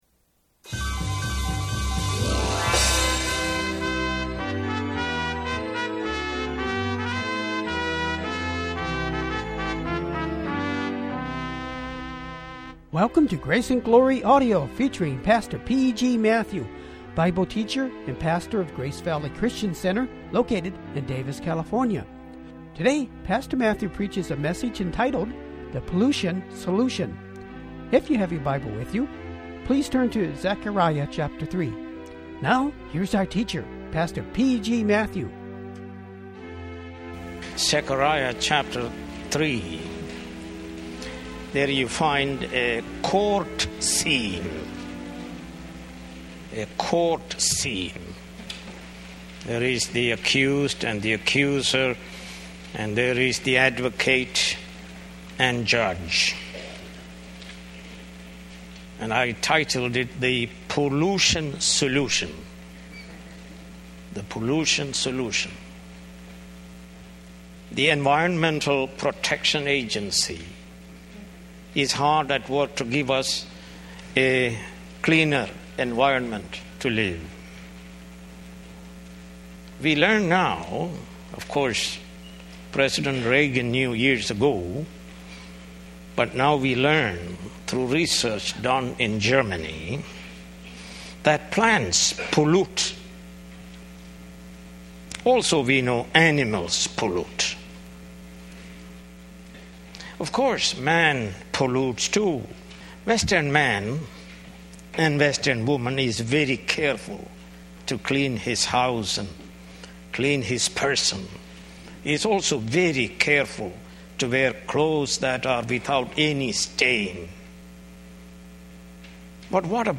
Sermons | Grace Valley Christian Center